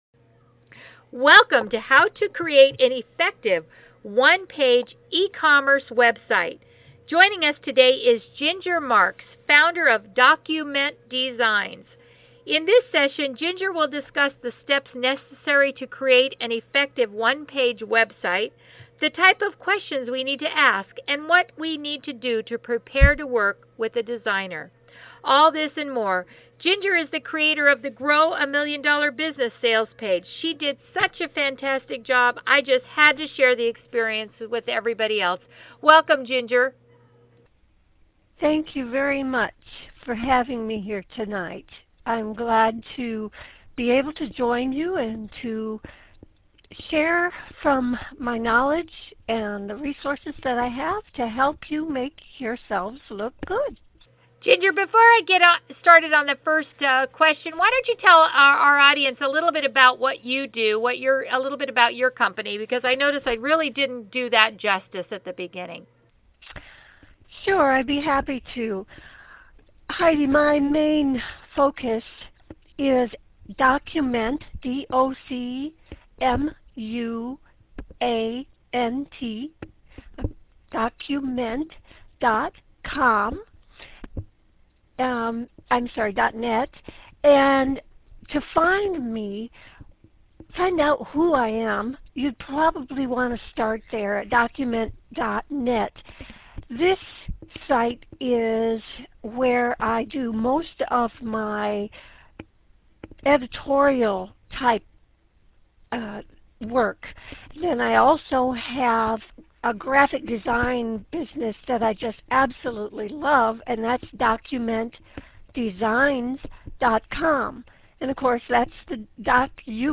Note: the sound at the beginning is a bit challenging but once you get past the first 3 minutes or so it is much easier to listen to.